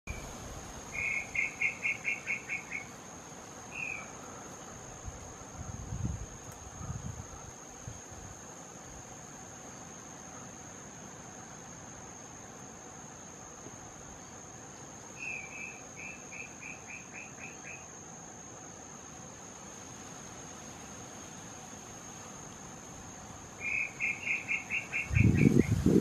Brown Tinamou (Crypturellus obsoletus)
Life Stage: Adult
Location or protected area: Paso Centurión
Condition: Wild
Certainty: Recorded vocal
Crypturellus-obsoletus.mp3